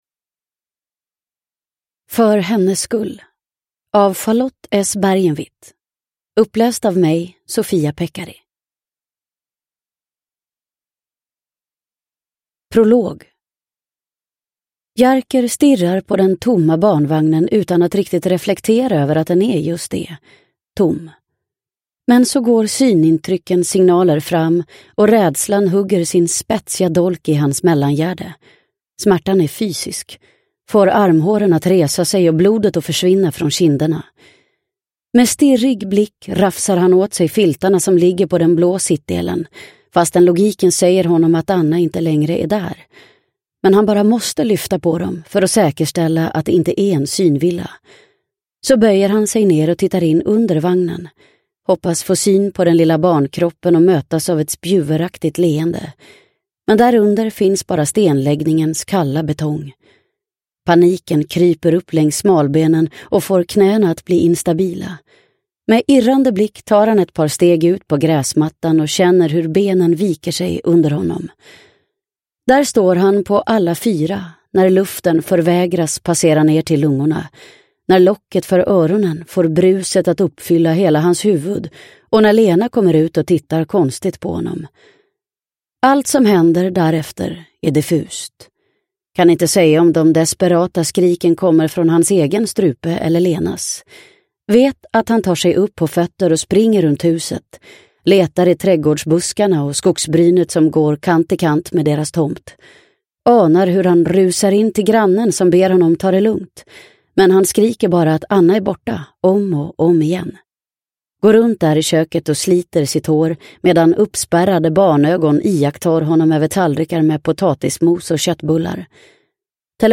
För hennes skull – Ljudbok – Laddas ner